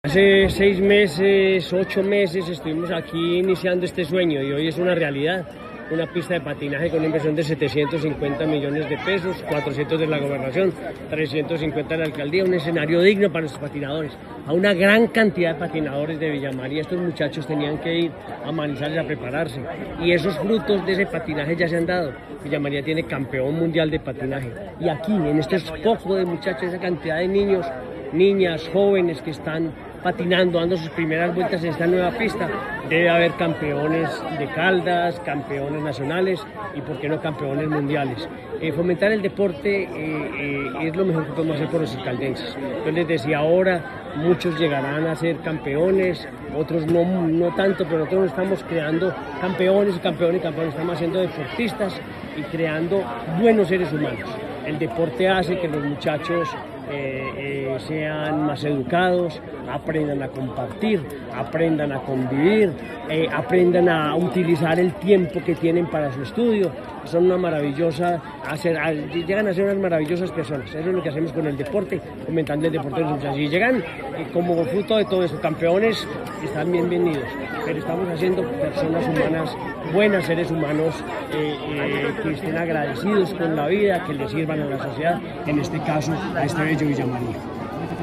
Henry Gutiérrez, gobernador de Caldas.
henry-gutierrez-gobernador-de-caldas.mp3